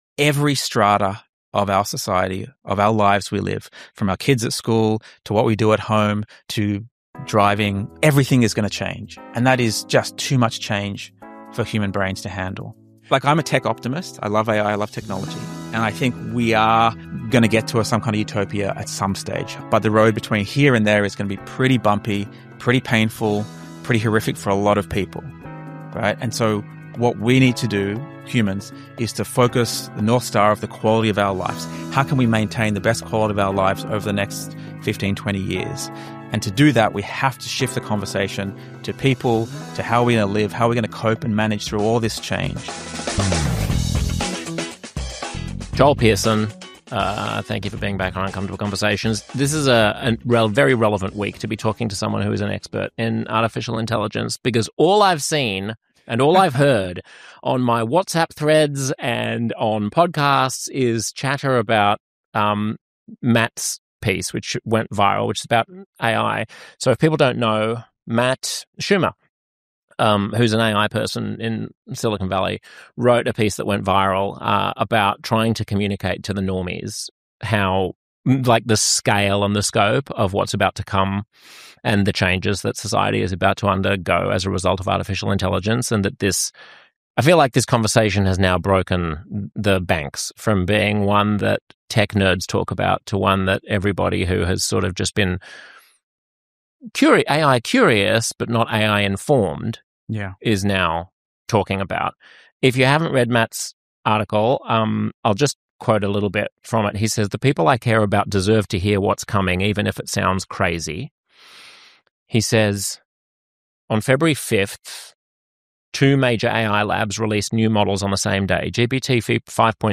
live on Substack